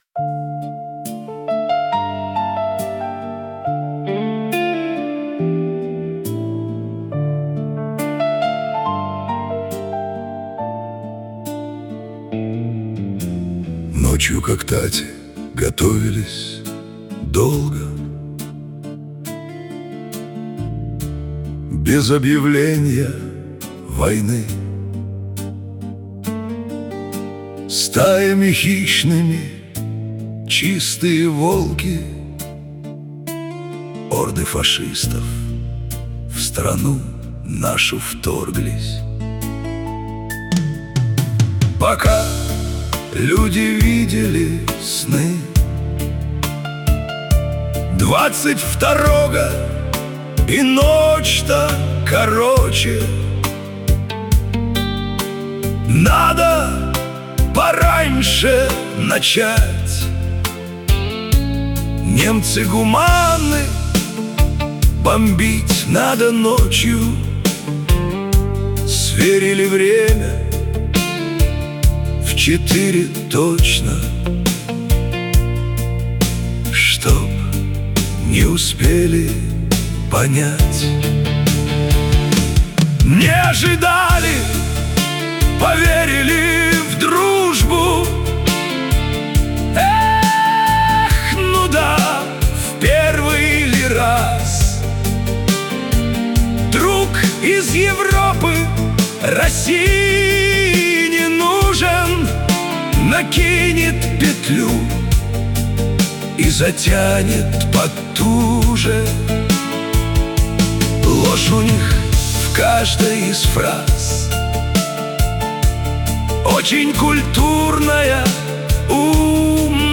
• Аранжировка: Ai
• Жанр: Военная